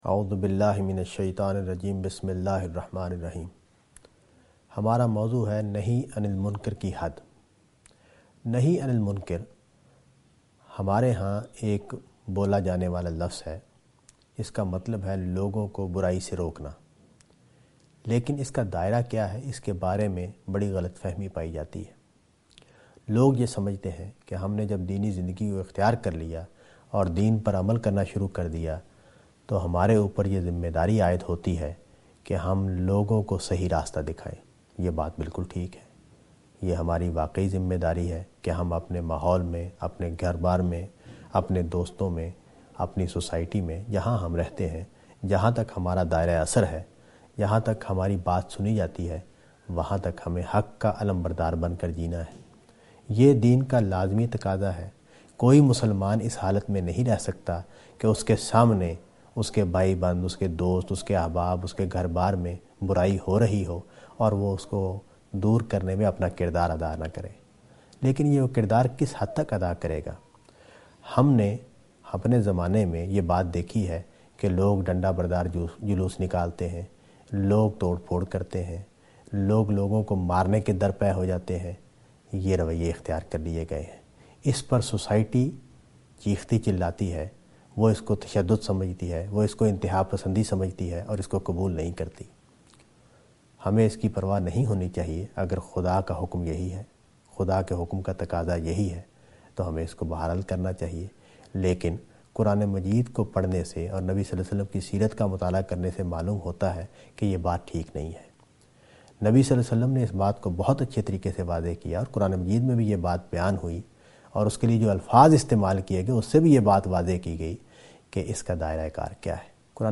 A short talk